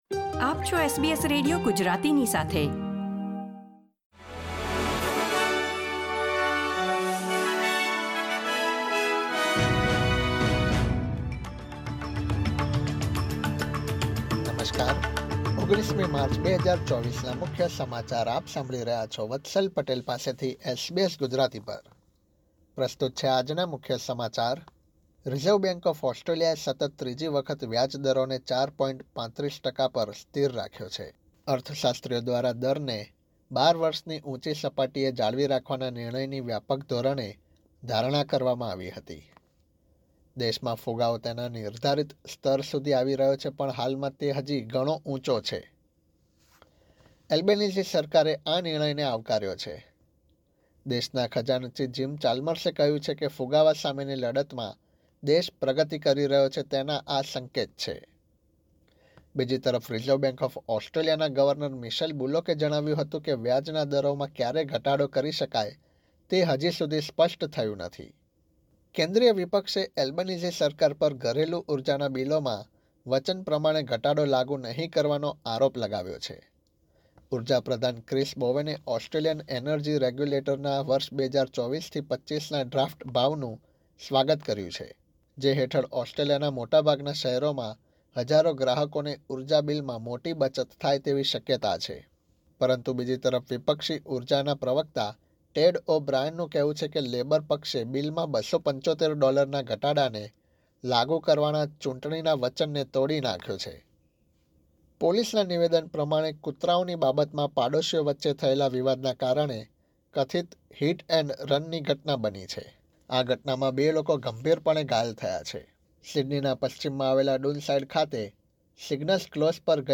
SBS Gujarati News Bulletin 19 March 2024